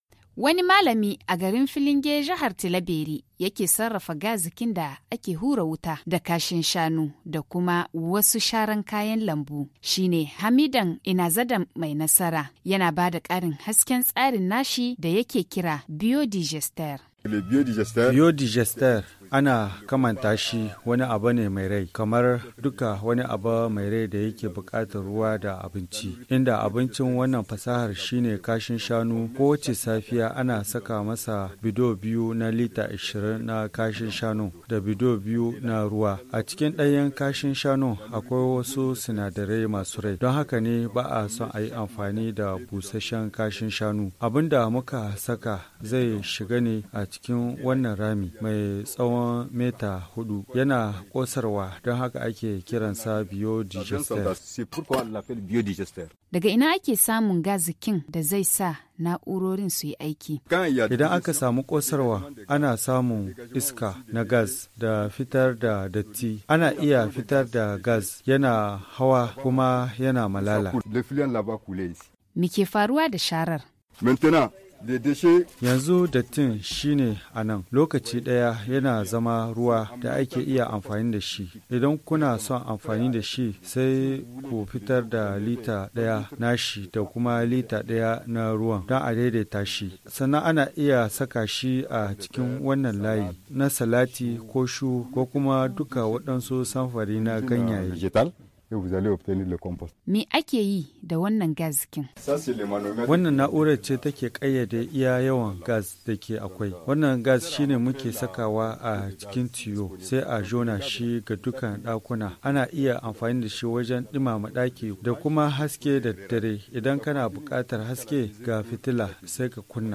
[Magazine] Du gaz domestique produit à Bonkoukou - Studio Kalangou - Au rythme du Niger